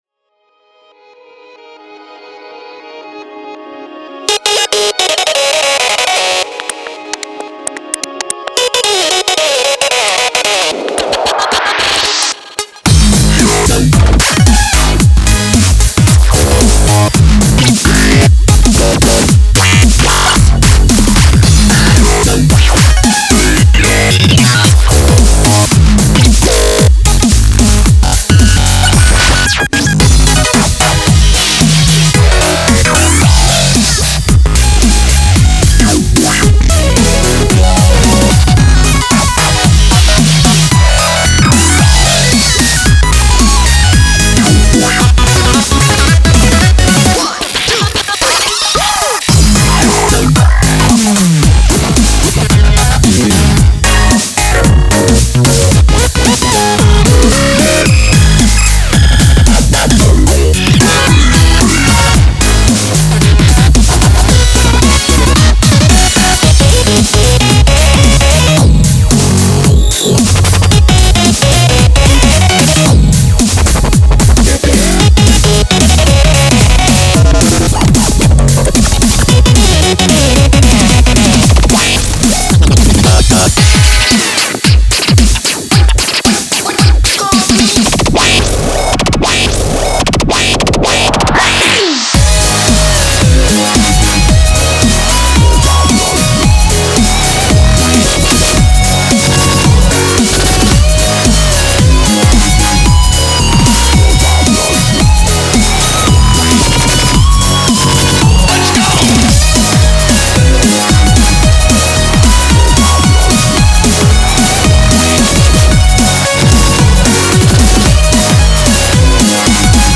BPM112-224
Audio QualityPerfect (High Quality)